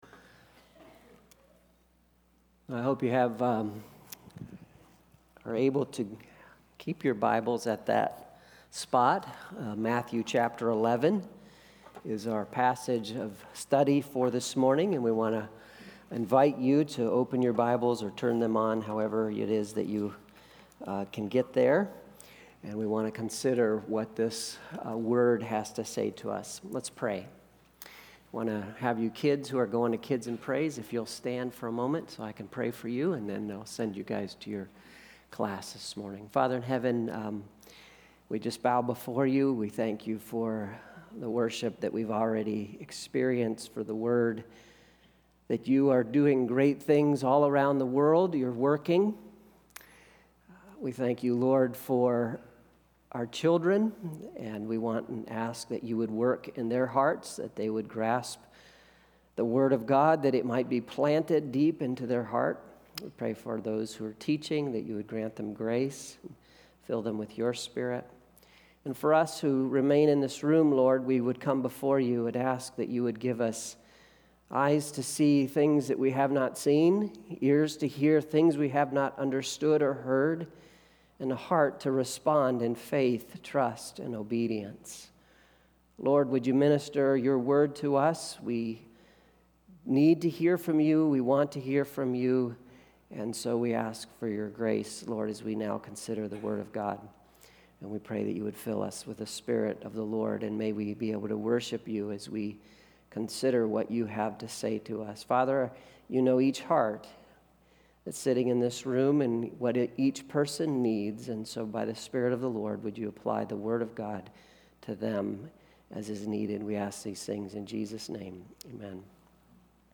Sermons | Staunton Alliance Church